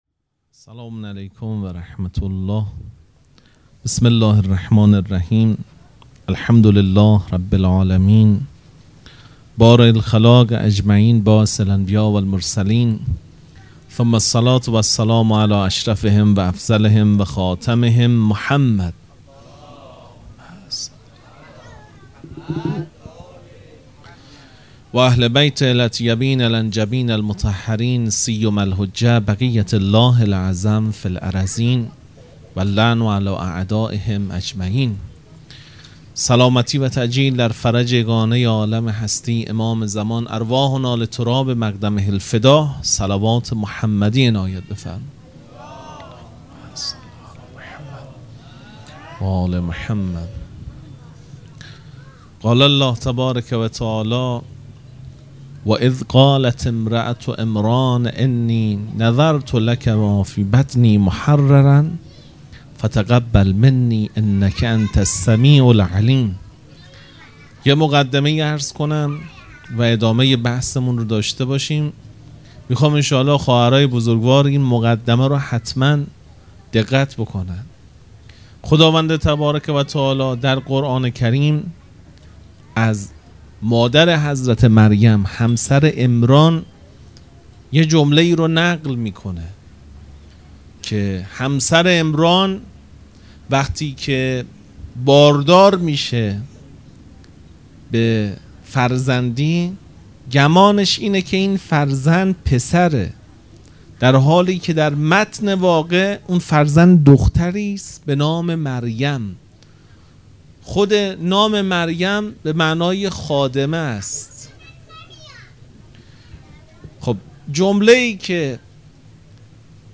هیئت مکتب الزهرا(س)دارالعباده یزد